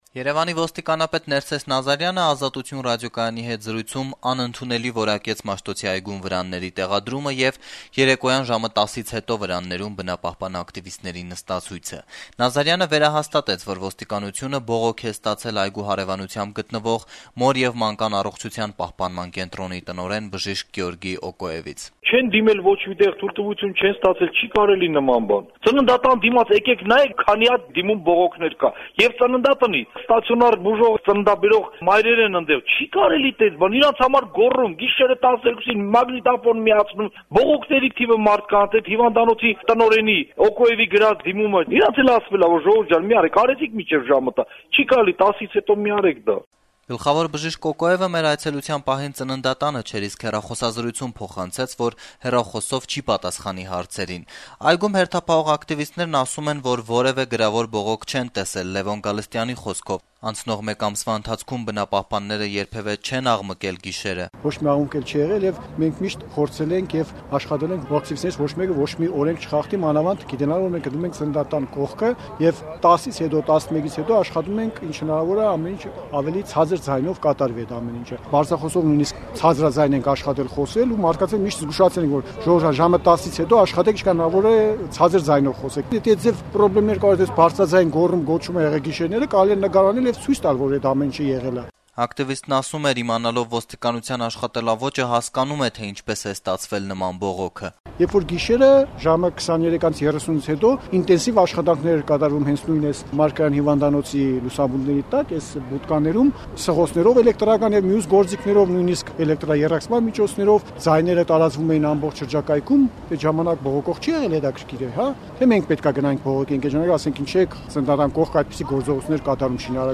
Երեւանի ոստիկանապետ Ներսես Նազարյանը այսօր «Ազատություն» ռադիոկայանի հետ զրույցում անընդունելի որակեց Մաշտոցի այգում վրանների տեղադրումը եւ երեկոյան ժամը 10-ից հետո բնապահպան ակտիվիստների նստացույցը: